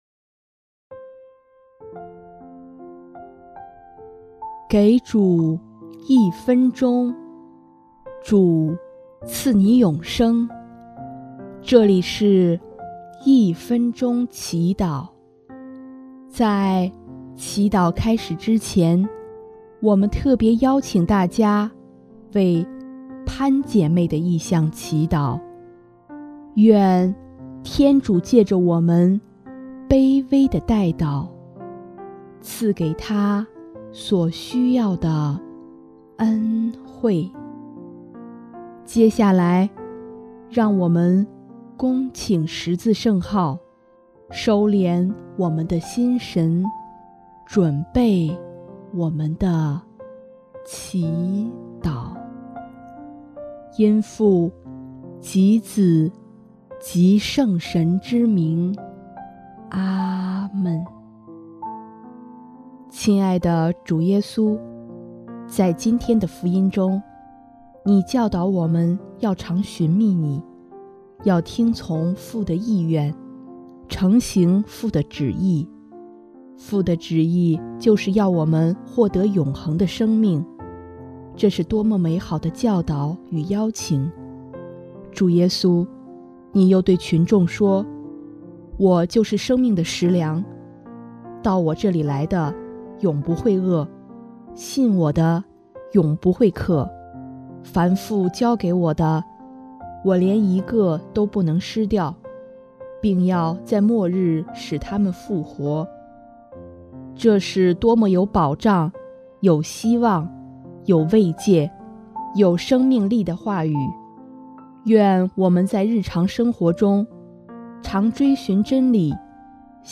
音乐： 主日赞歌《谁若吃了这食粮》